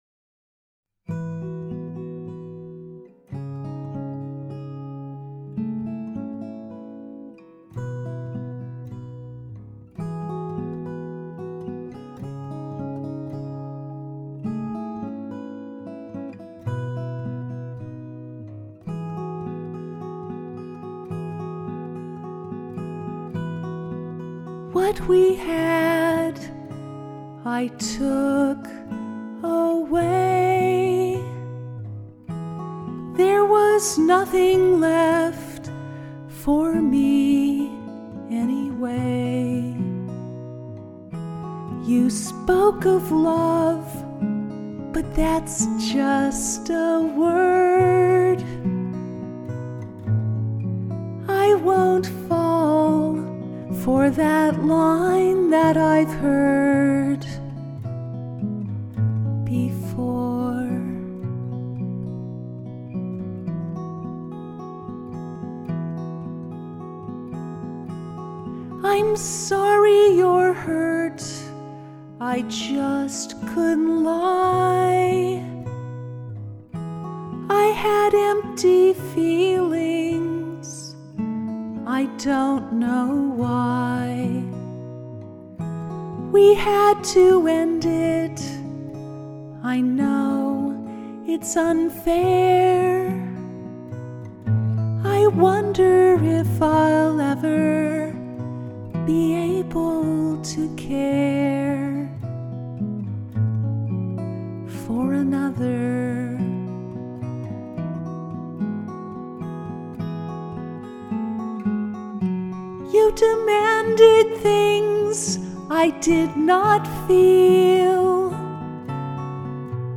I’m nervous to share my singing voice.